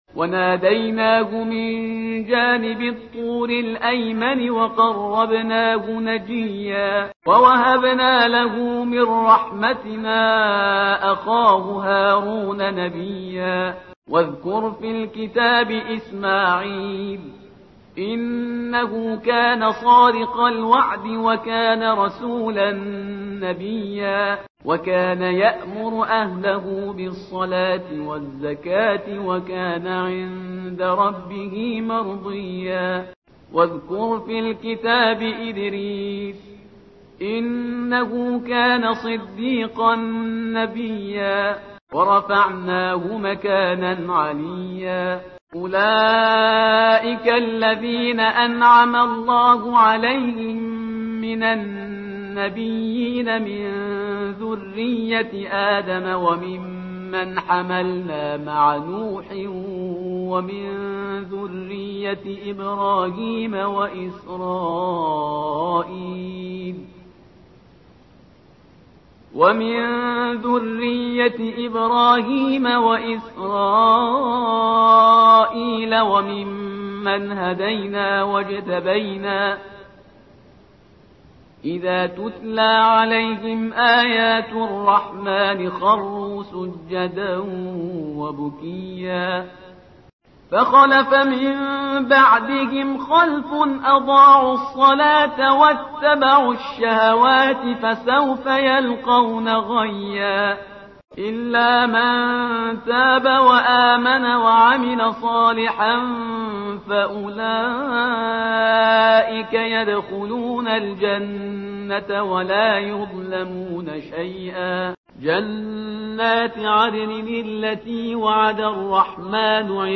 ترتیل قرآن صفحه 309